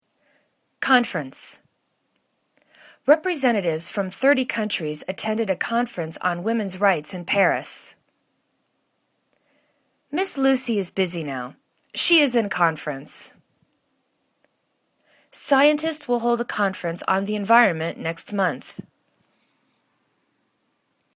con.fe.rence    /konfәrәns/     [C]